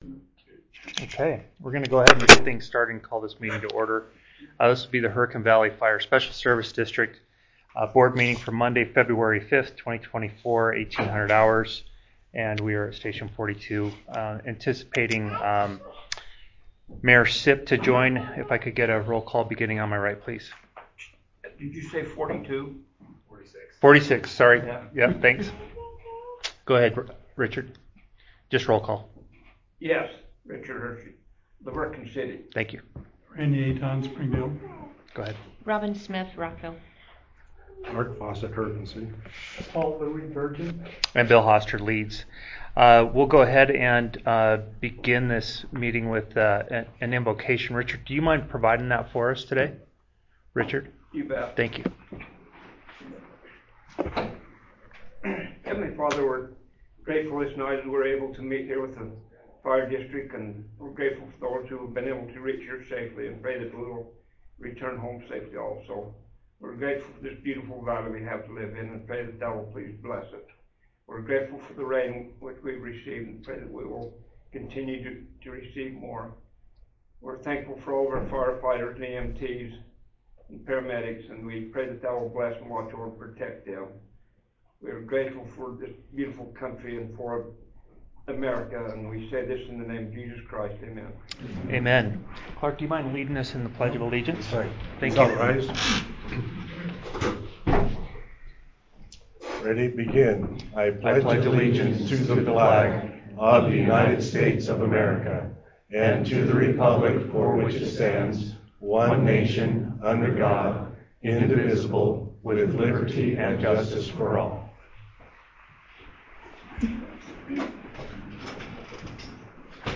Download 2024-2-5 HVF Board Meeting Recording.mp3 (opens in new window)